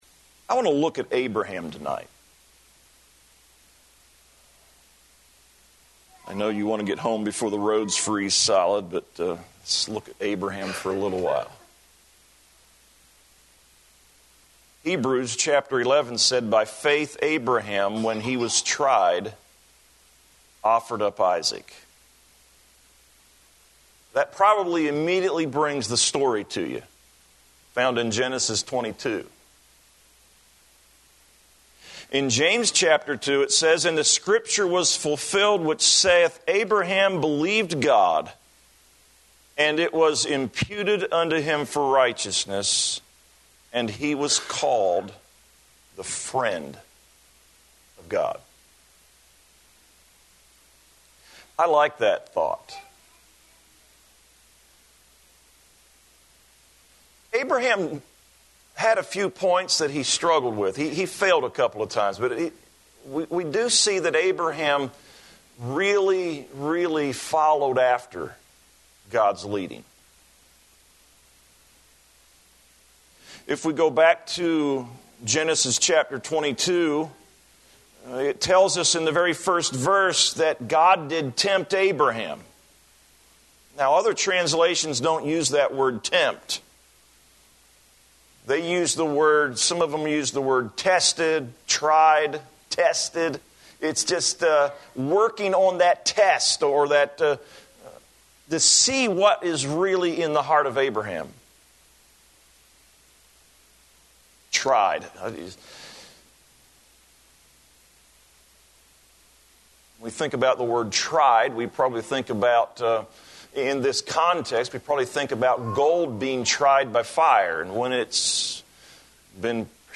Consecration